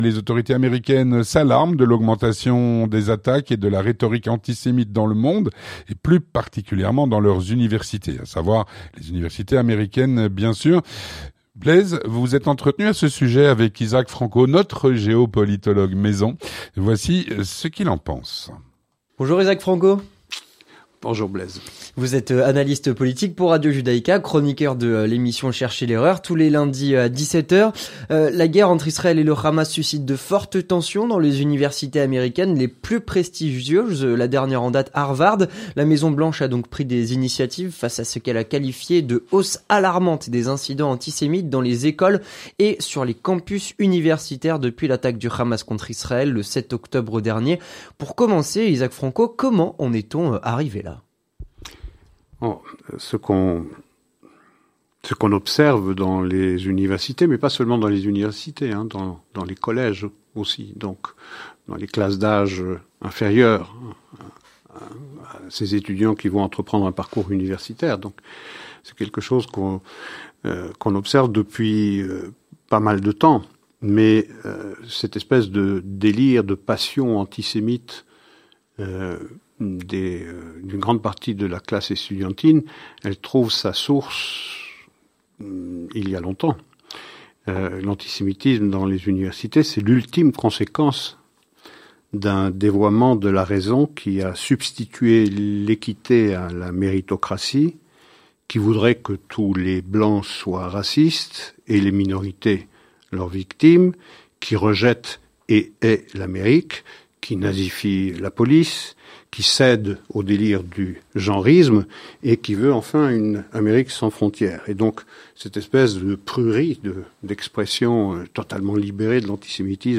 L'entretien du 18H - L'augmentation des actes et propos antisémites sur les campus universitaires américains.